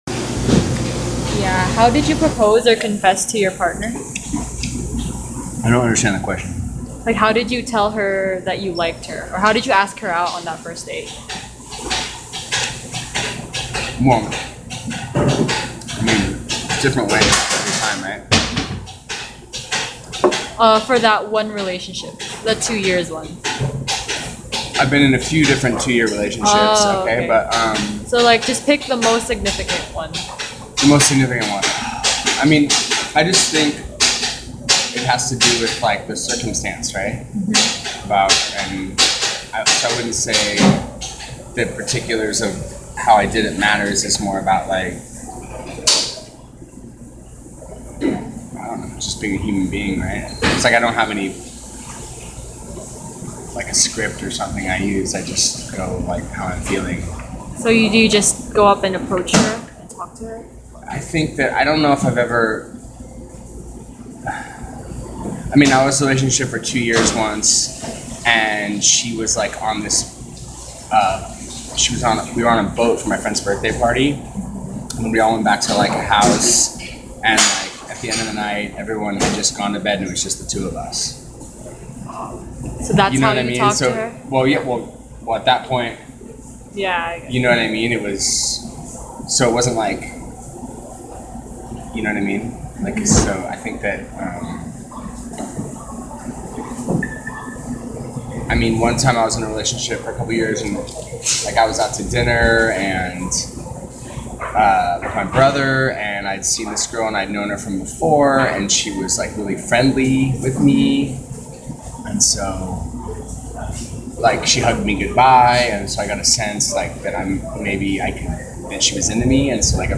I met my second interviewee in Yonkers Cafe, on Noriega Street.
The sound clip below is a recording of my interview with him.